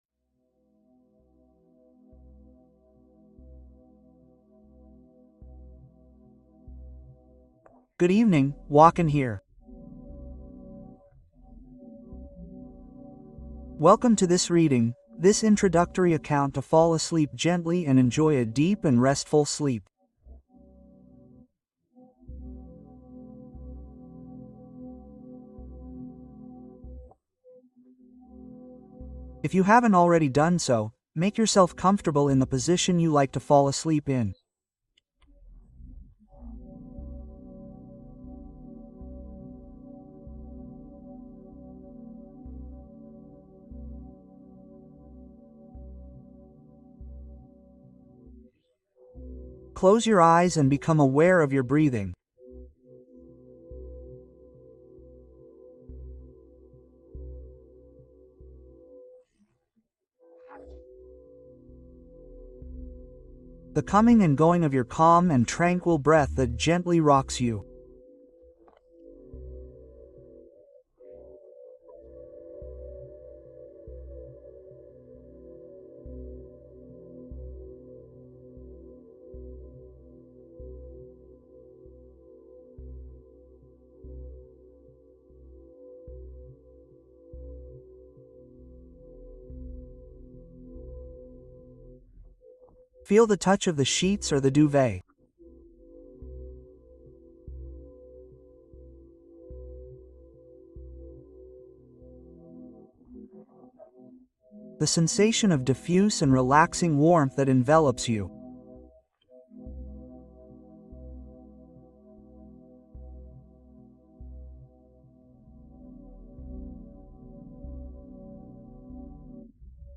Histoire magique pour s'endormir : sommeil profond et réparateur garanti